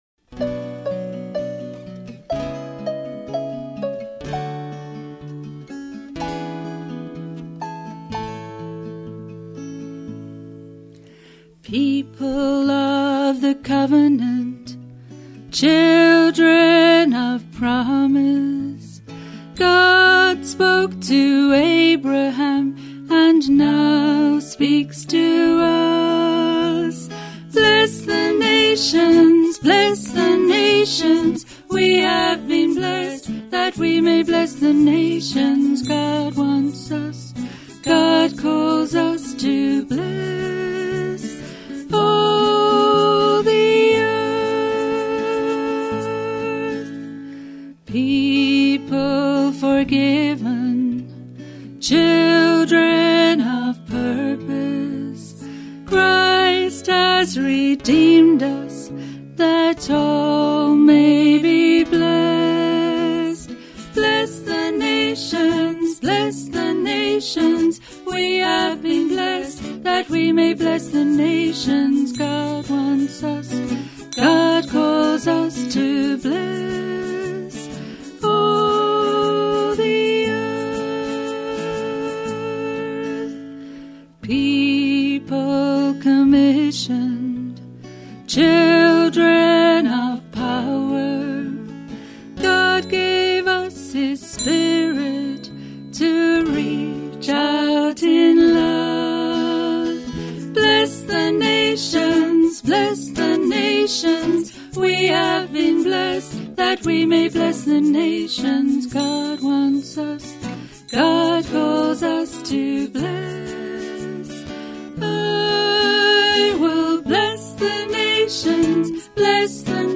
full mix
25th Anniversary version